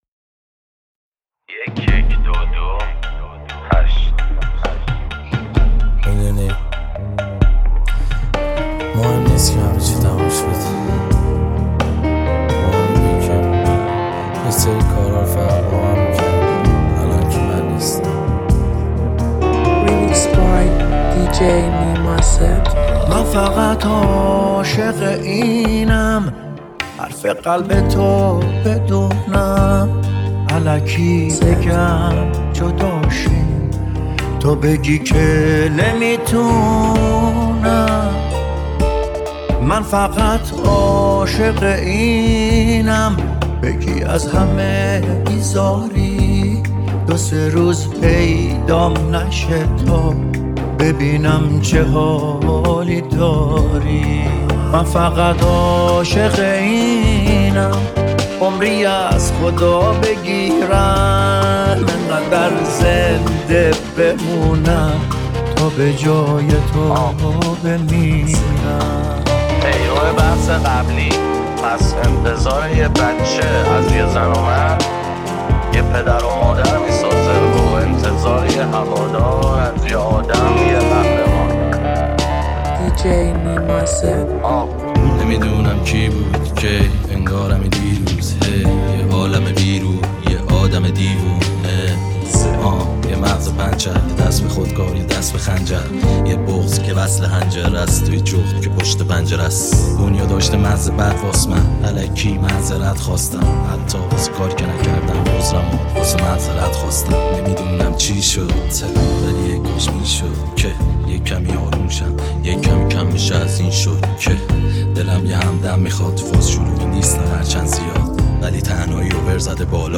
ریمیکس شاد
ریمیکس رپ